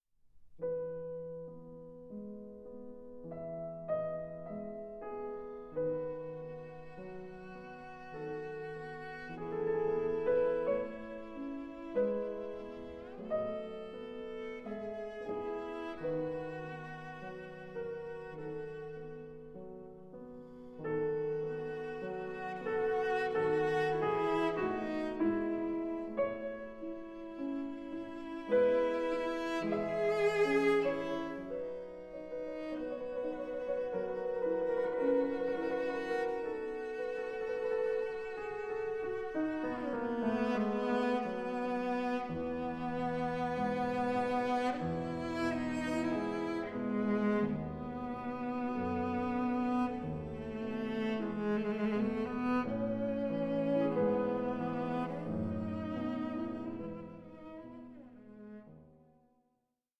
EQUAL, PROFOUND PARTNERSHIP OF CELLO AND PIANO